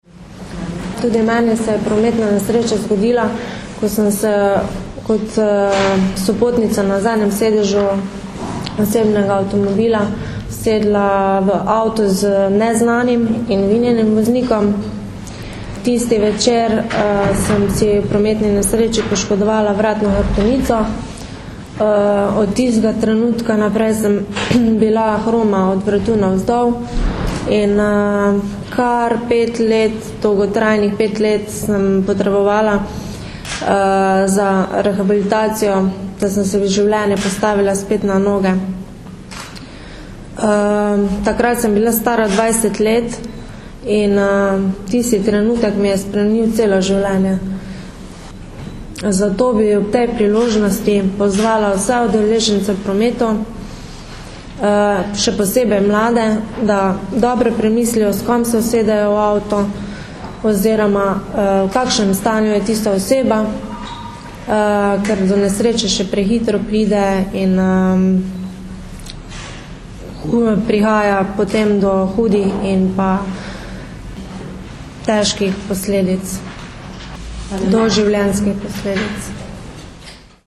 Zvočni posnetek izjave